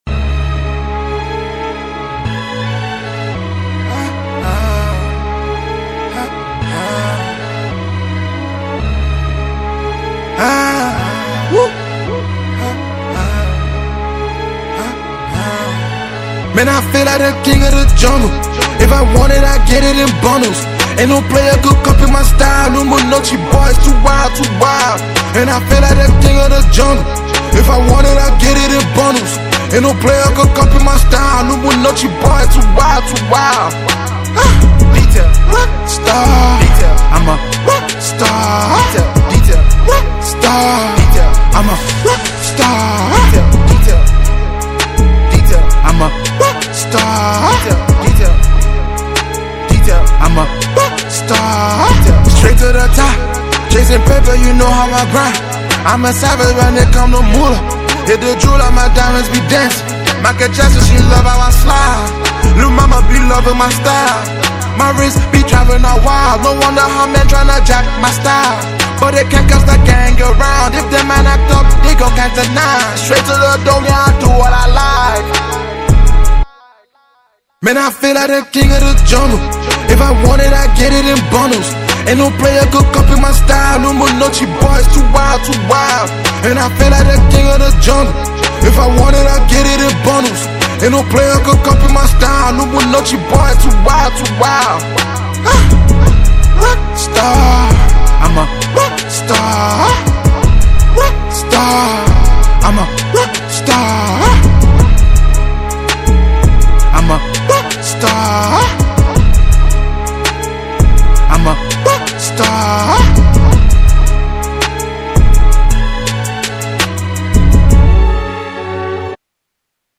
Talented rapper and songwriter